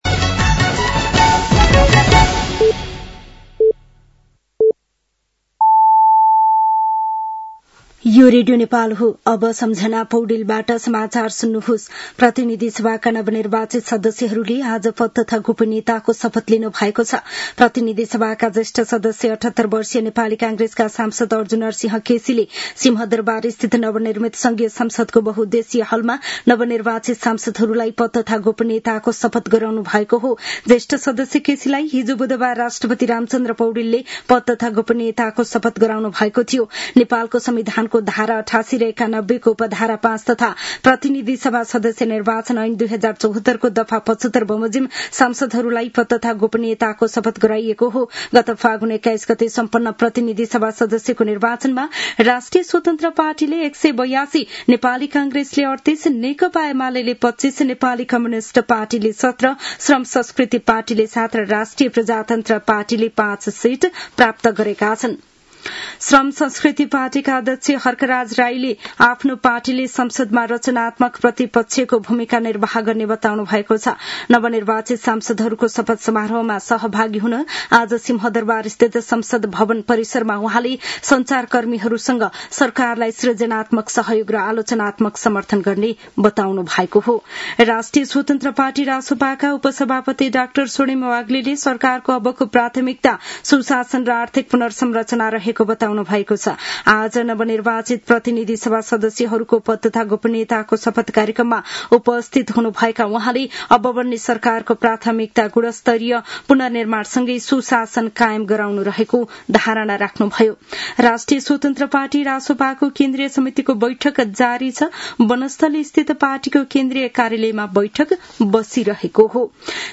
साँझ ५ बजेको नेपाली समाचार : १२ चैत , २०८२
5.-pm-nepali-news-1-4.mp3